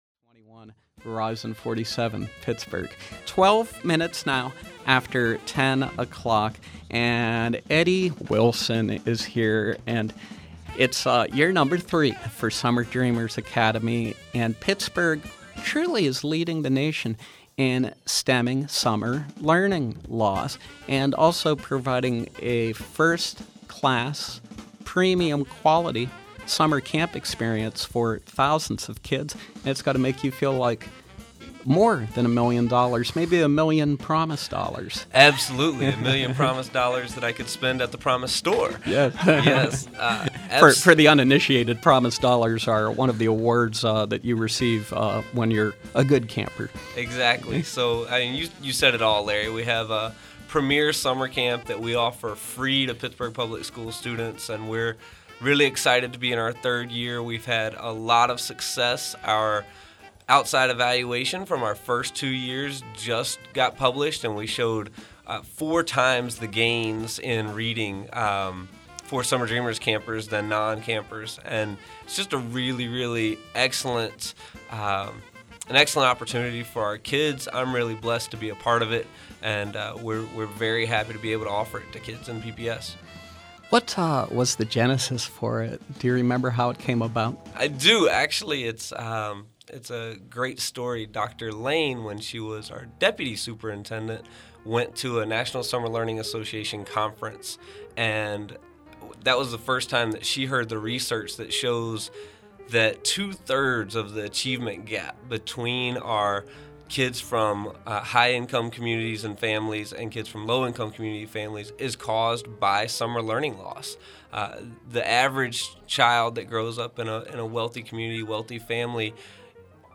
Interns, activity providers, and district personnel from Summer Dreamers Academy 2012 join us to demonstrate how Pittsburgh is leading the nation in preventing summer learning loss and closing the achievement gap for students in PPS grades K-8.